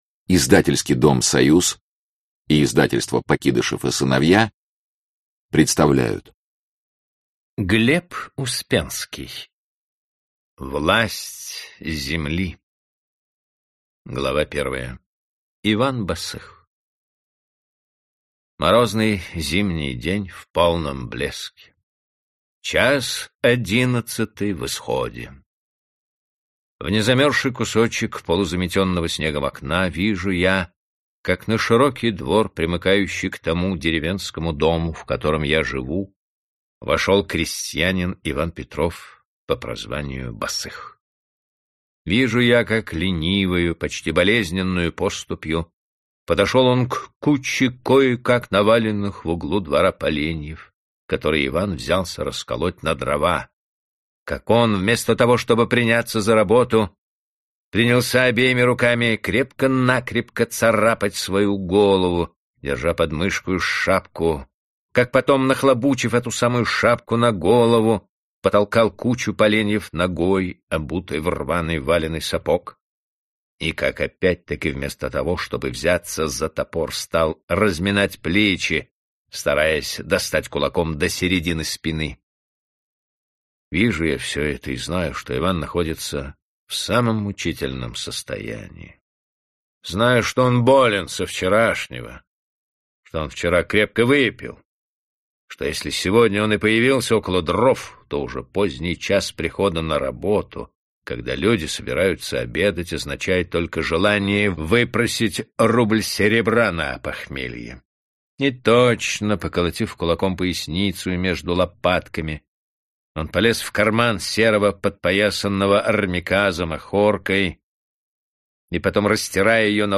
Аудиокнига Власть земли | Библиотека аудиокниг